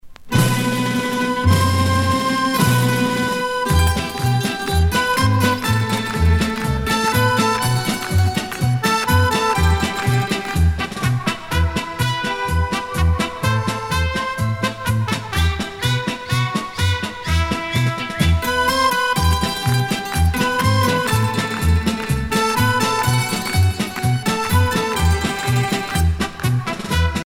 danse : kazatchok
Pièce musicale éditée